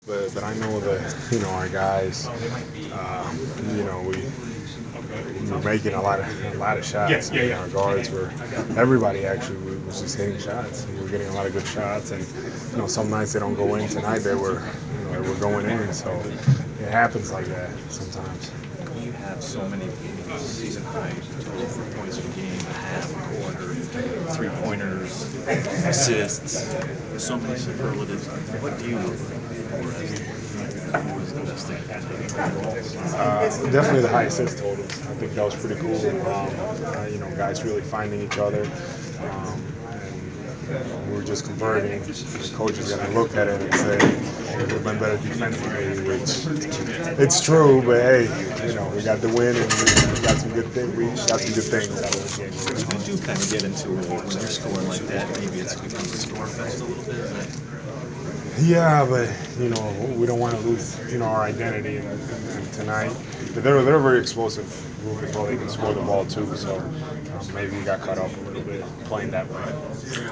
Inside the Inquirer: Postgame presser with Atlanta Hawks’ Al Horford (3/9/15)
He scored 18 points and grabbed four rebounds in the victory. Topics included the team’s record-setting performance, defending Kings’ center DeMarcus Cousins and the offensive success of the Hawks.